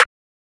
pluggnbsnare3.wav